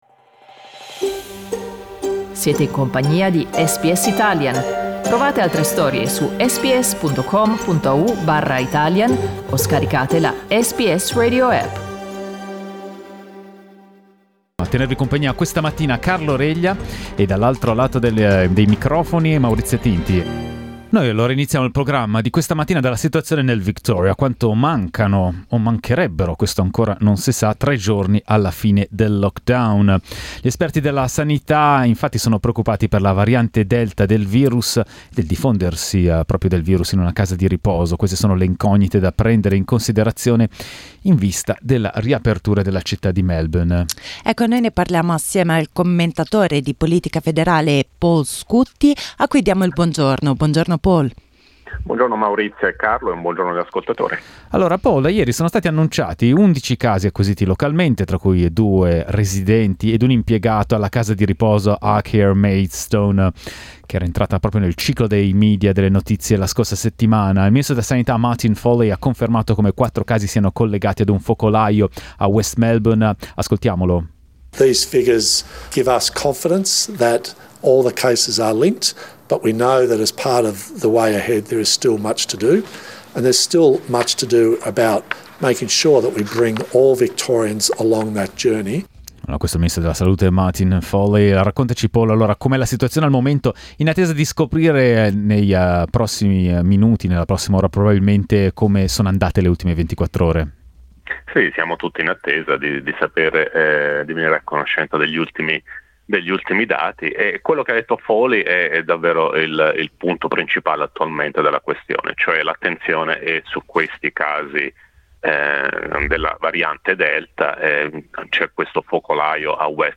commentatore di politica federale australiana